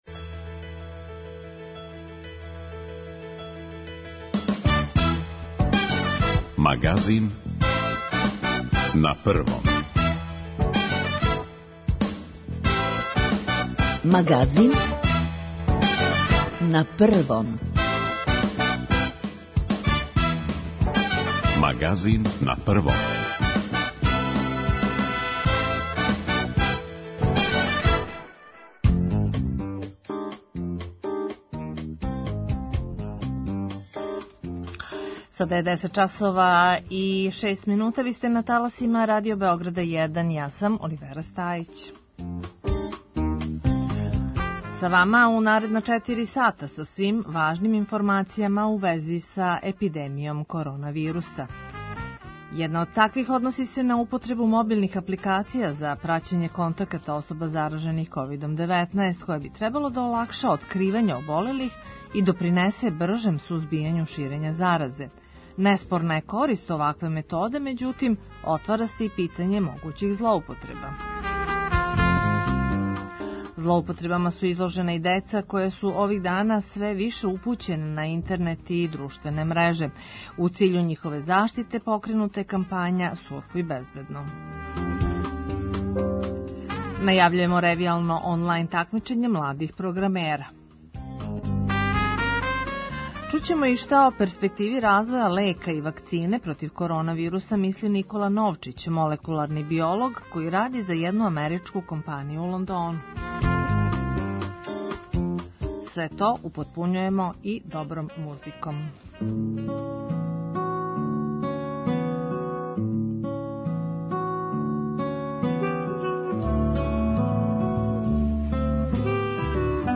Доносимо најновије информације о епидемији која је захватила велики део света, тражимо савете стручњака о томе како се понашати у условима епидемије и ванредног стања, пратимо стање на терену, слушамо извештаје наших репортера из земље и света.